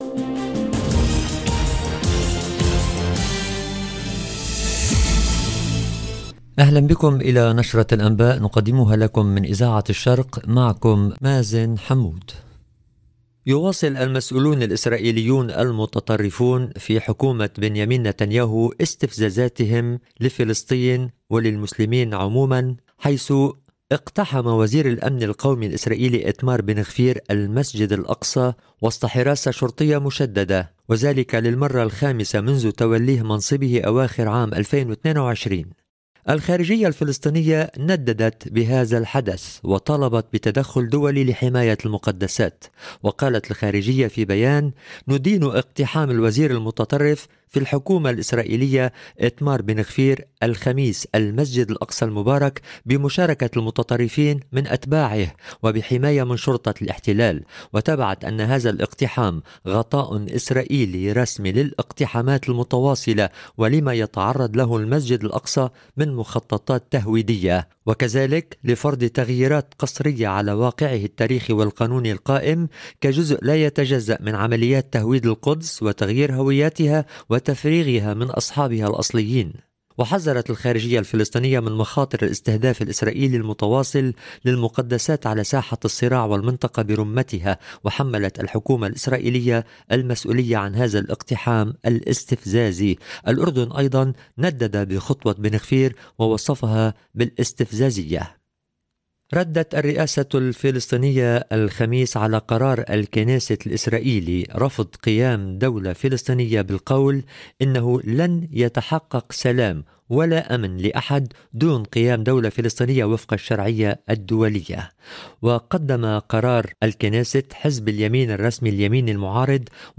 LE JOURNAL EN LANGUE ARABE DU SOIR DU 18/07/24